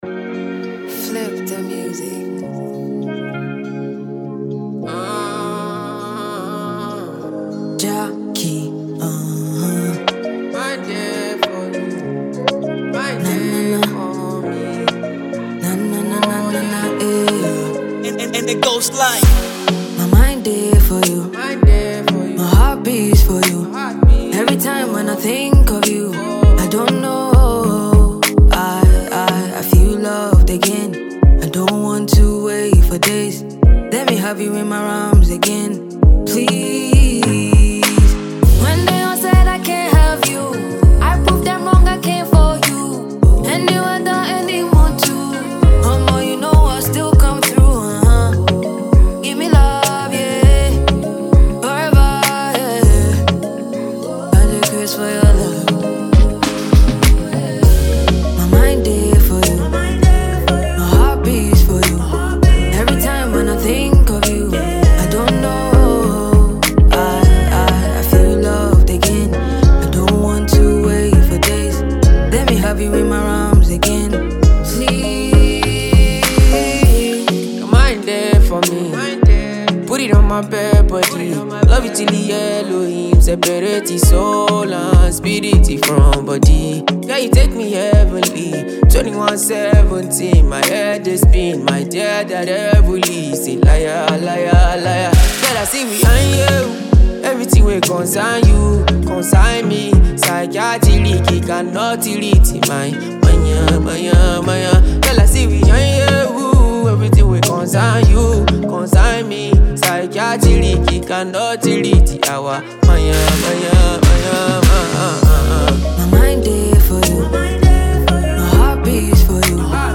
Ghanaian Afro fusion singer
amazing vocals